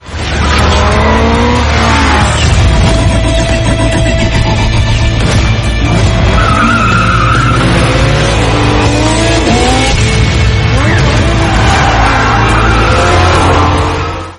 Project cars ambiance.mp3 (113.06 Ko)